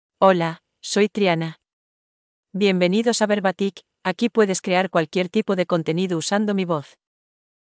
FemaleSpanish (Spain)
TrianaFemale Spanish AI voice
Triana is a female AI voice for Spanish (Spain).
Voice sample
Listen to Triana's female Spanish voice.
Triana delivers clear pronunciation with authentic Spain Spanish intonation, making your content sound professionally produced.